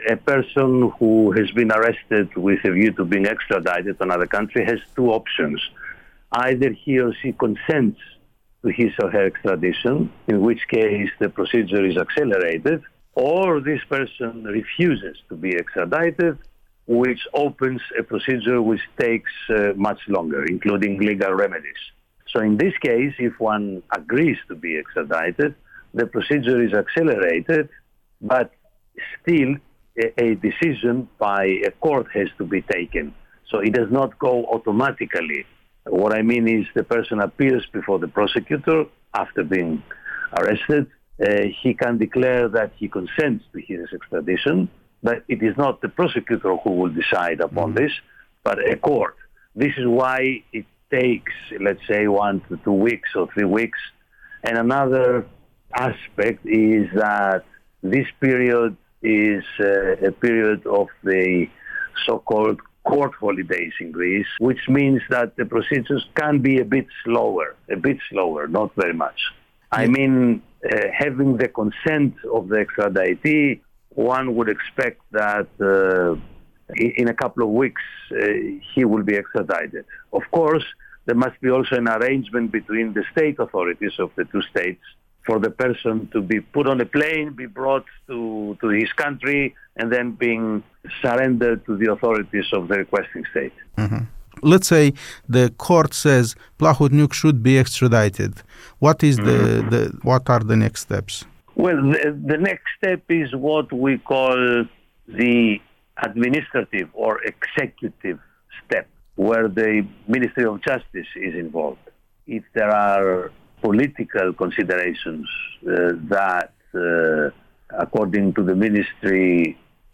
La zece zile de la reținerea lui Vladimir Plahotniuc la Atena, pe un mandat de urmărire Interpol la cererea R. Moldova, autoritățile moldovene și oligarhul capturat rămân prinse într-o dispută: cine vrea mai tare ca el să fie adus la Chișinău, cine și ce face pentru a grăbi sau, dimpotrivă, a tergiversa procesul. Europa Liberă a discutat cu un expert grec în probleme de extrădare.